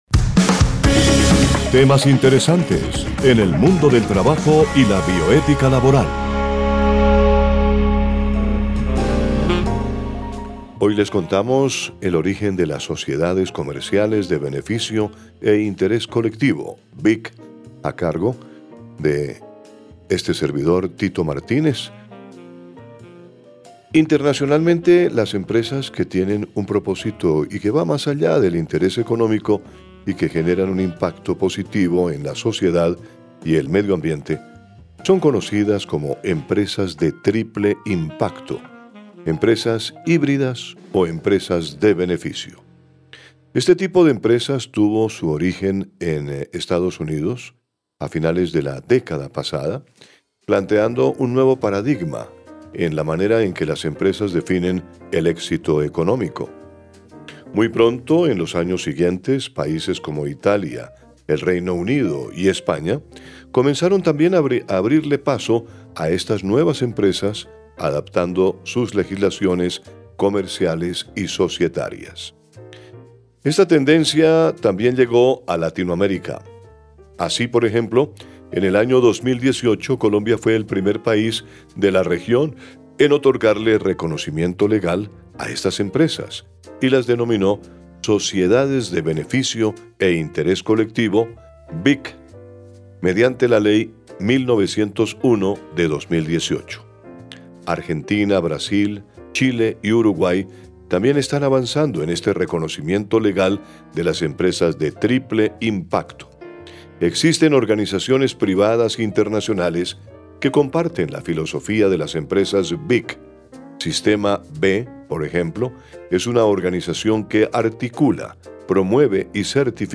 Narrador: